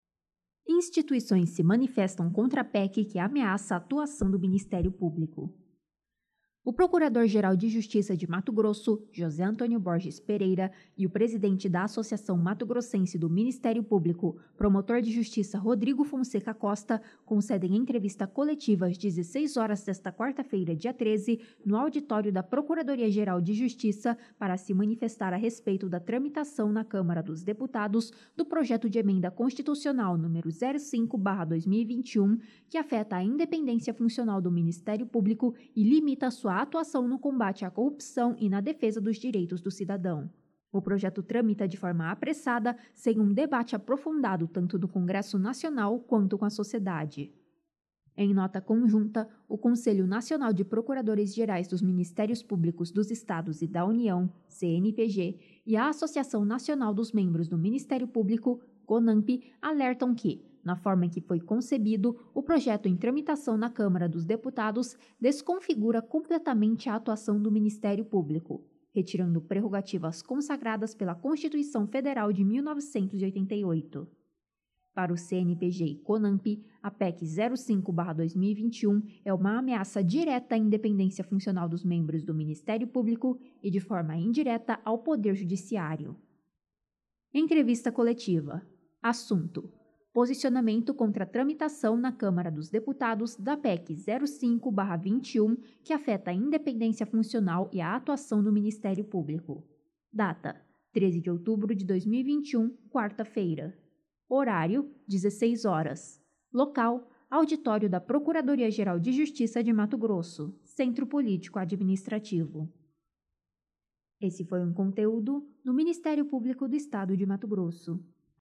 ENTREVISTA COLETIVA
Local: Auditório da Procuradoria-Geral de Justiça de Mato Grosso (Centro Político Administrativo)